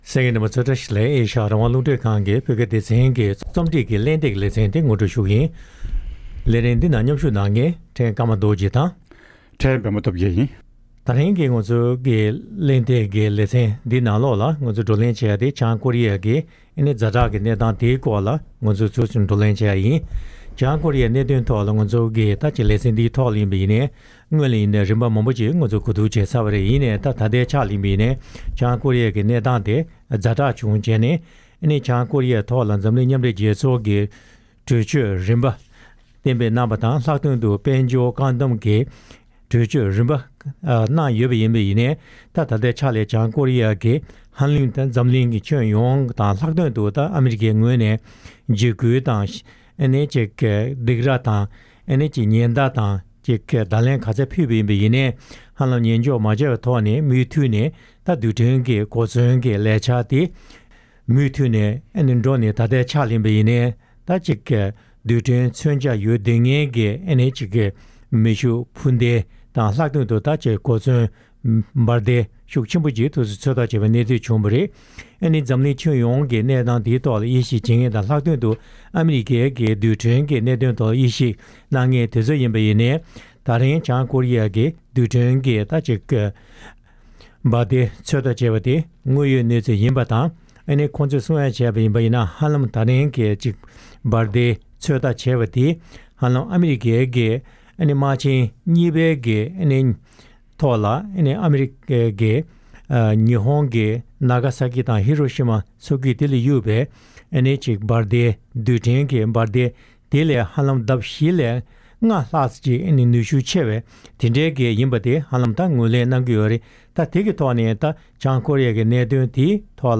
༄༅། །ཐེངས་འདིའི་རྩོམ་སྒྲིག་པའི་གླེང་སྟེགས་ཀྱི་ལེ་ཚན་ནང་། འཛམ་བུ་གླིང་གི་རྒྱལ་ཁབ་འདྲ་མིན་ནས་དགག་བྱ་གནང་མིན་ལ་ལྟོས་མེད། བྱང་ཀོ་རི་ཡས་རྡུལ་ཕྲན་མཚོན་ཆ་ཚོད་ལྟ་བྱེད་བཞིན་ཡོད་པ་ལྟར། བྱང་ཀོ་རི་ཡའི་ཛ་དྲག་གི་གནད་དོན་སྐོར་གླེང་མོལ་ཞུས་པ་ཞིག་གསན་རོགས་གནང་།